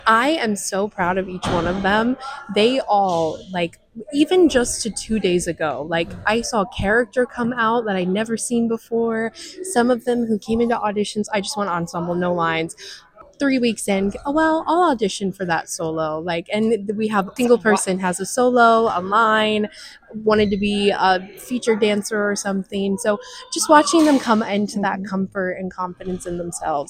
The cast of Mary Poppins